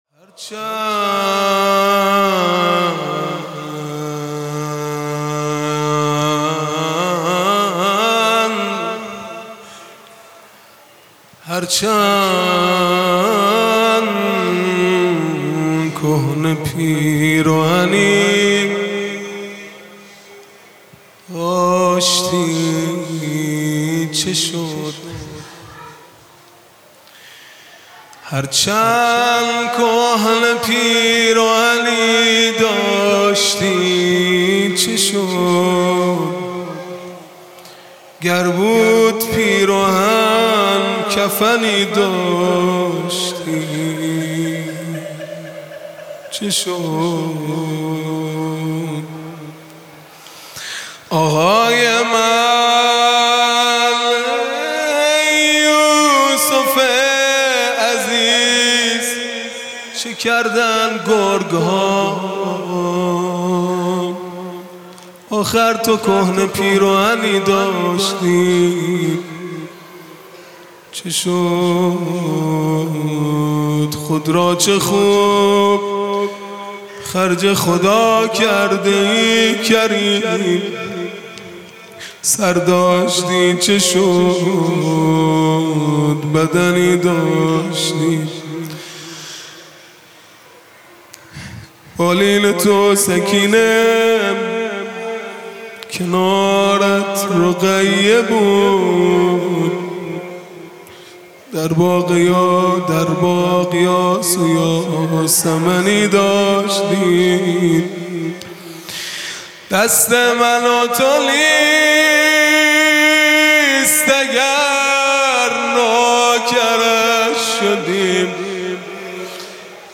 مناجات پایانی | هرچند کهنه پیراهنی داشتی چه شد | ۲۸ تیر ۱۴۰۲
محرم الحرام ۱۴۴5 | شب دوم | چهارشنبه 28 تیر ماه ۱۴۰2